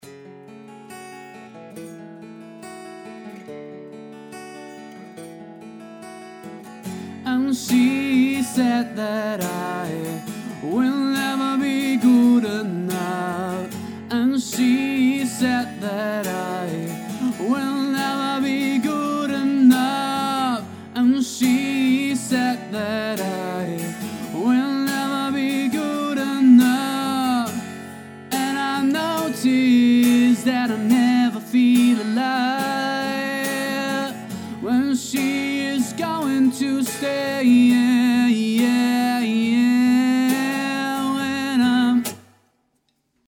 Hallo, ich spiele Gitarre und Singe und probiere mich auch immer wieder am Aufnehmen und "Abmischen" meiner Ideen aus.
Zur Zeit nehme ich meine Akustik Gitarre mit einem Rode m5 mp(Stereo Paar) auf, panne die Spuren nach links und rechts und mit einem Rode NT1-A singe ich dann über die aufgenommenen Gitarren Spuren.
Aufnahmeraum ist mein Schlaf/Wohnzimmer(andere Zimmer zum Aufnehmen habe ich nicht) der ist von der Akustik her(nach meinem unqualifizierten Urteil) nicht so der Bringer(viel Hall).
Mir geht es letztendlich darum am Schluss besser klingende Songs zu haben, ich habe mal unbearbeitetes Rohmaterial angehängt, vielleicht kann ja mal jemand was dazu sagen.